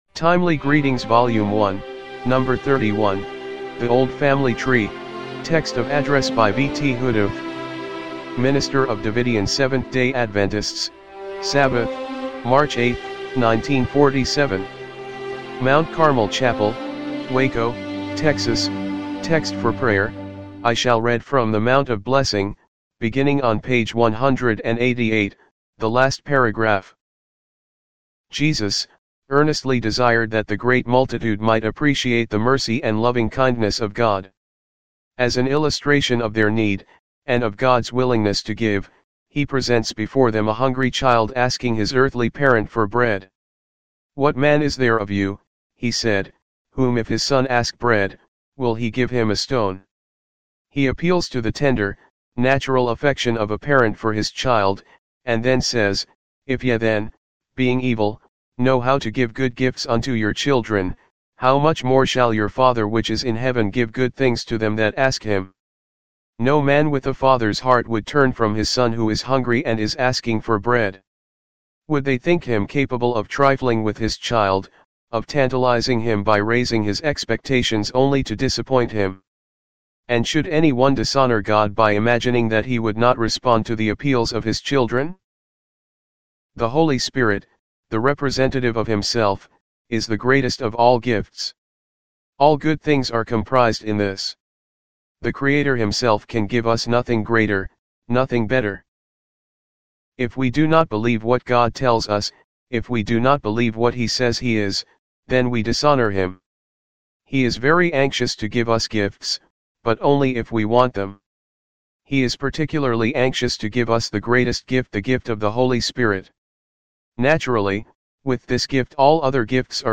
timely-greetings-volume-1-no.-31-mono-mp3.mp3